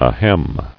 [a·hem]